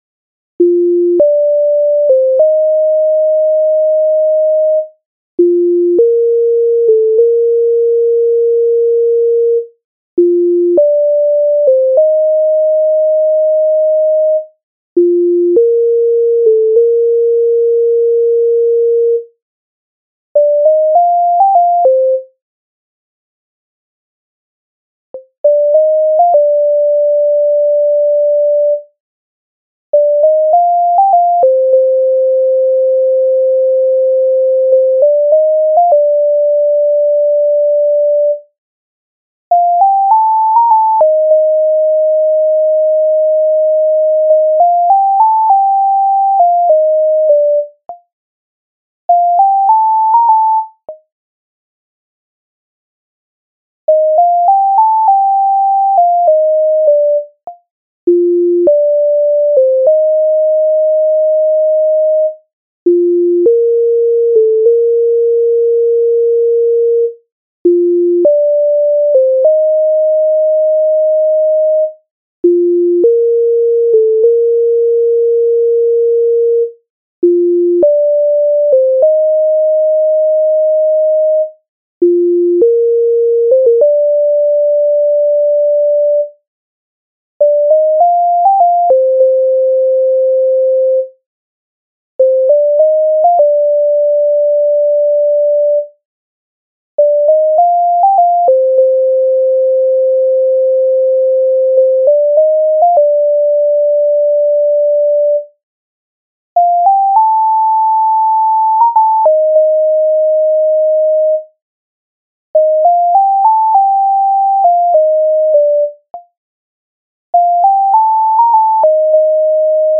MIDI файл завантажено в тональності B-dur